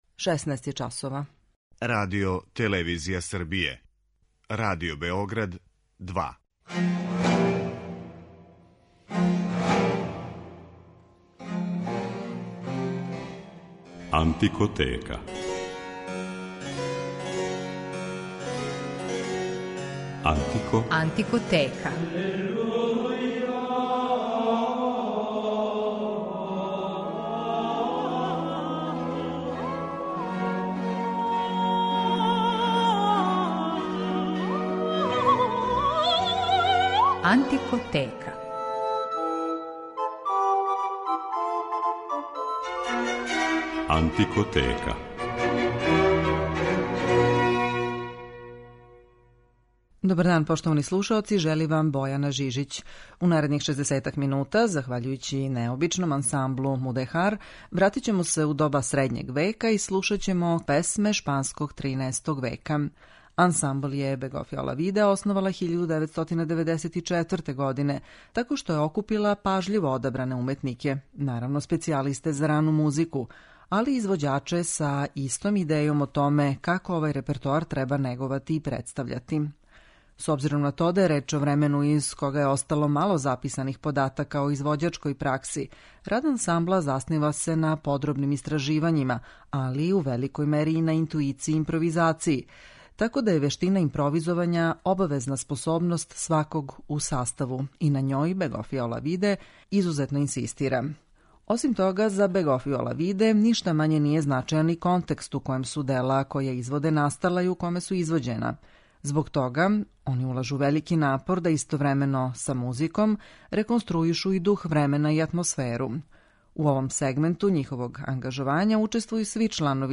Захваљујући необичном ансамблу 'Мудехар', у данашњој емисији ћемо се вратити у доба средњег века и слушаћемо музику шпанског 13. столећа.
Као и велики део музичке историје ове земље, и шпанску музику овог периода одликује велика разноврсност проистекла из укрштања различитих народа који су живели на њеном тлу и прожимања њихових култура - европске, арапске и јеврејске.
али и музику магреб-андалузијске традиције анонимних аутора.